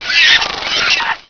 sound / gargoyle / pain75.wav
pain75.wav